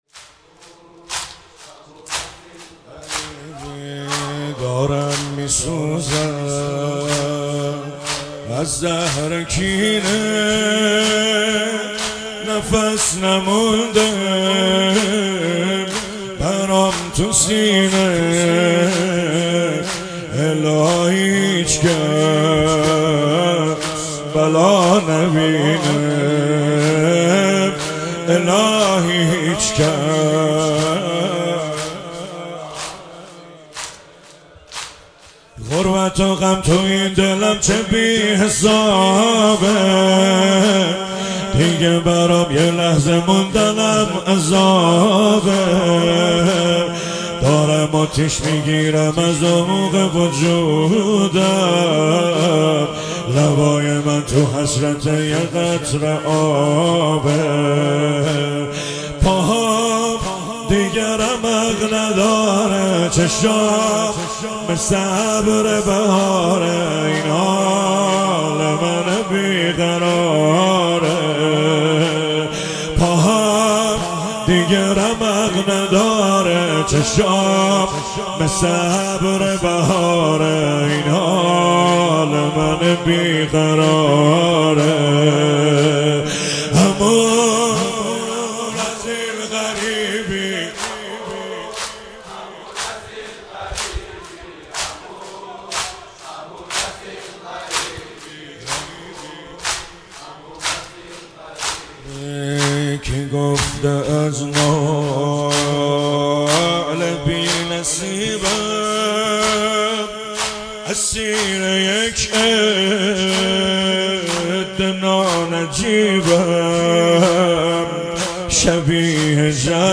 شهادت امام جواد (ع) 98
زمینه - دارم میسوزم